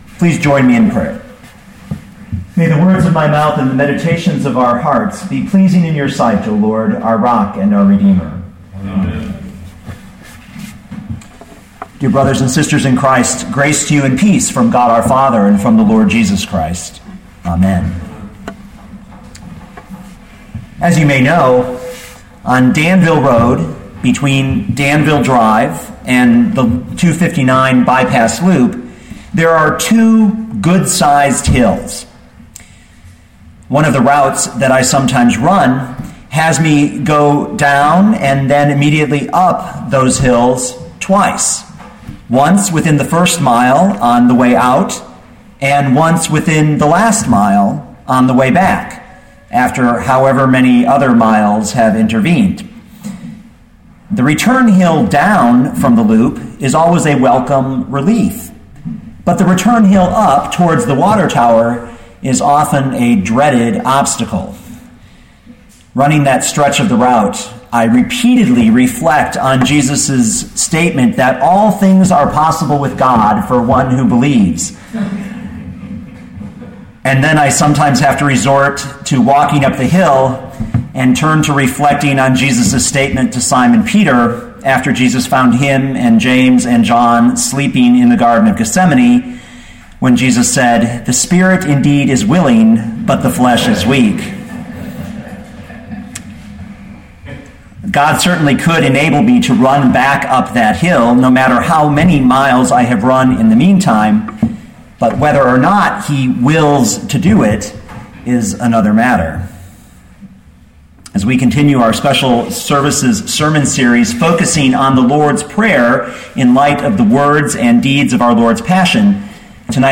2013 Matthew 6:10 Listen to the sermon with the player below, or, download the audio.